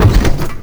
sounds / suspension / compress_heavy_3.wav
compress_heavy_3.wav